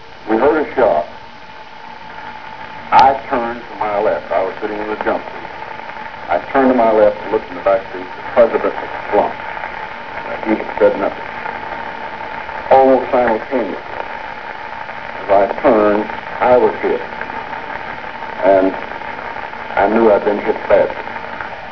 Connally.wav Sound file of Governor John Connally's interview.